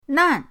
nan4.mp3